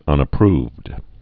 (ŭnə-prvd)